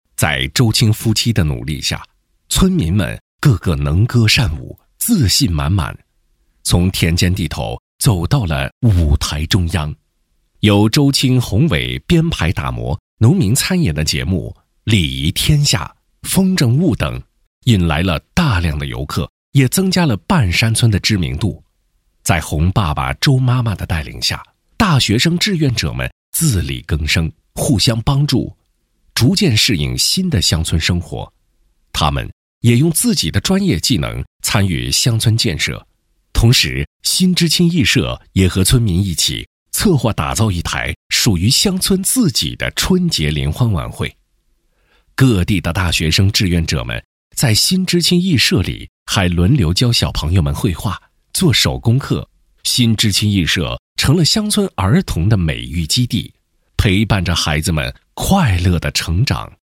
淘声配音网，专题，宣传片配音，专业网络配音平台